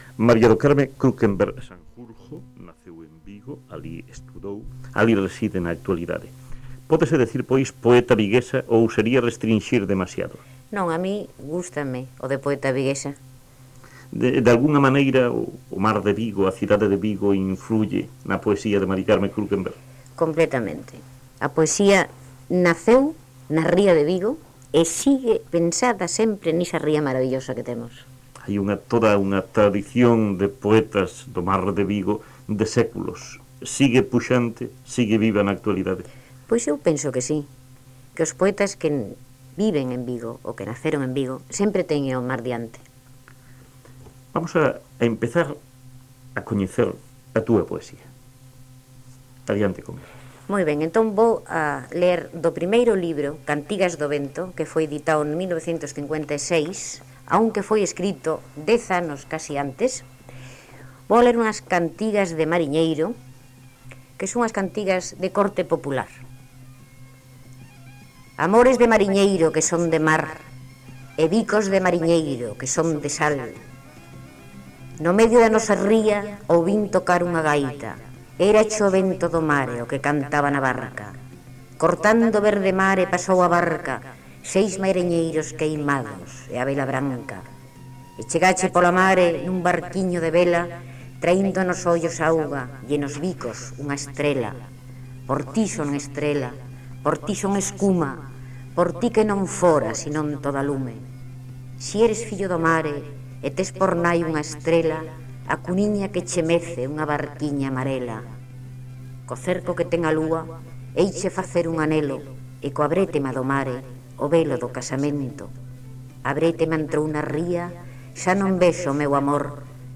Entrevista a María do Carme Kruckenberg – Poetas na súa voz – Colectivo Egeria